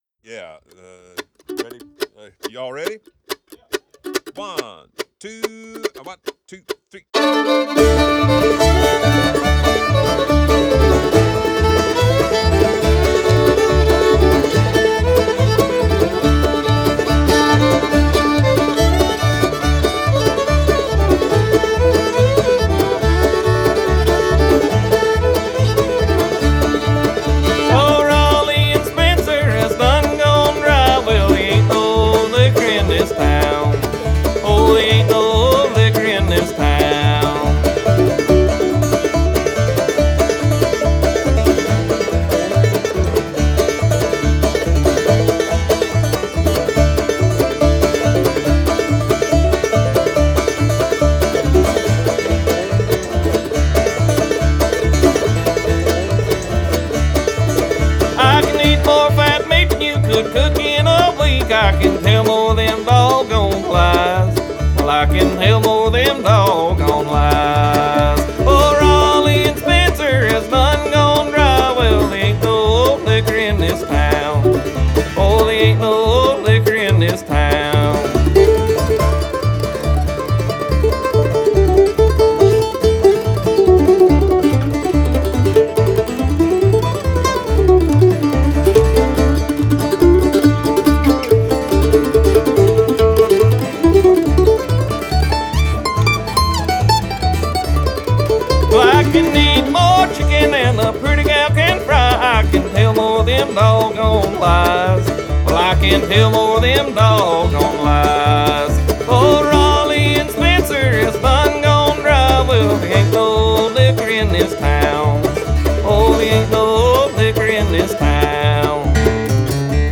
Genre: Bluegrass, Americana, Folk